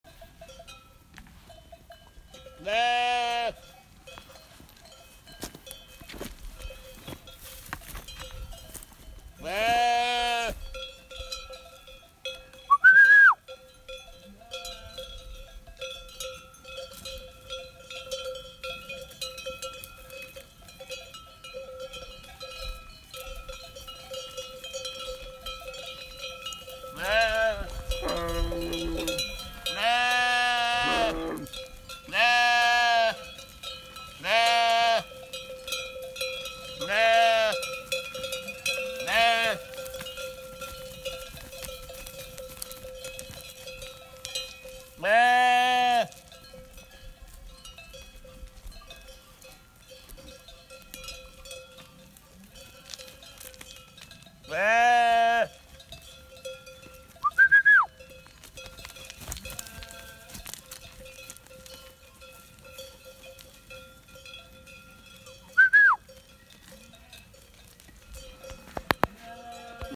Bells and sheep
bell bells ding dong lambs Recording sheep sound effect free sound royalty free Sound Effects